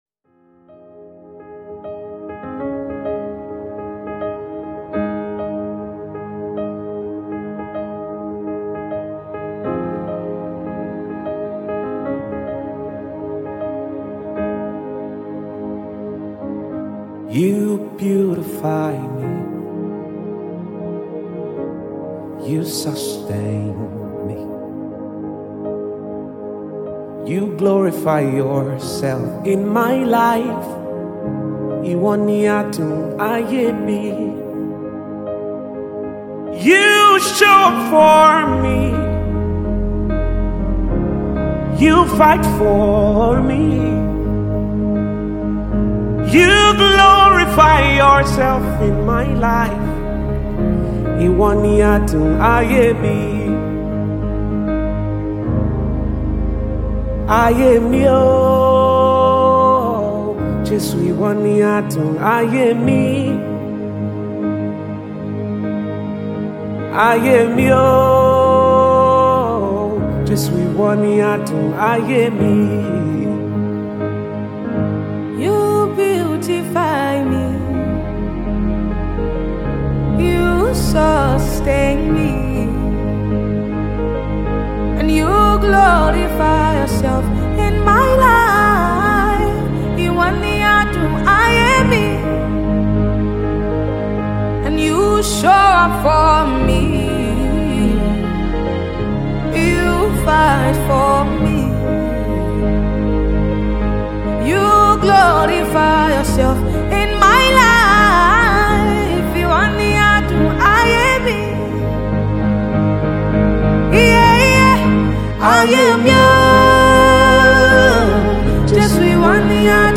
Talented Nigerian Singer